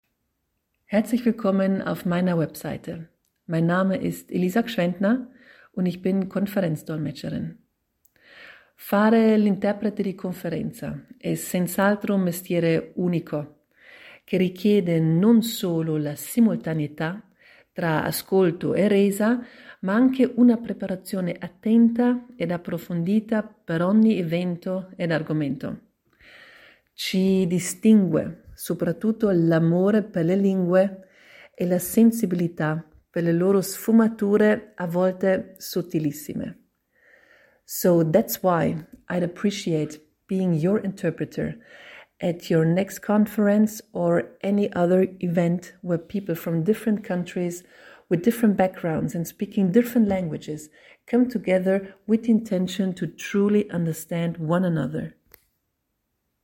Stimmprobe:
Stimme & Präsenz: Ruhig, klar, sympathisch – für ein angenehmes Hörerlebnis.
interprete-willkommensnachricht.mp3